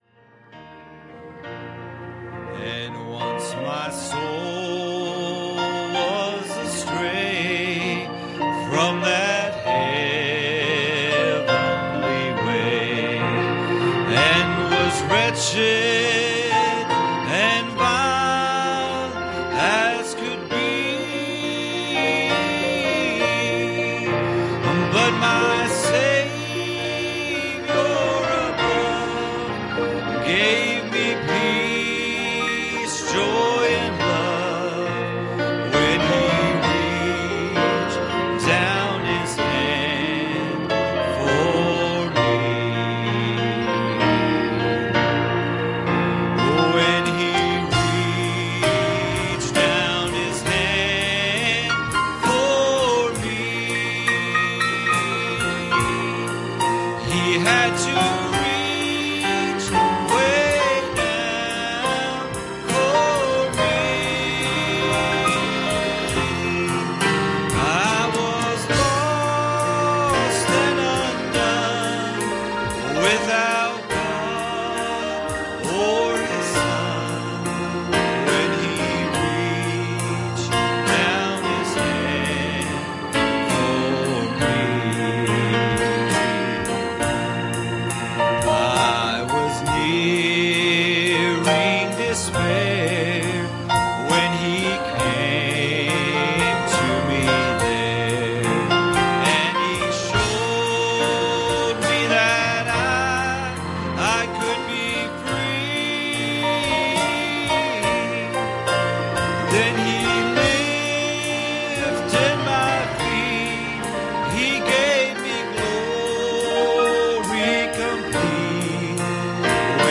Ezra 7:11 Service Type: Sunday Morning "We pray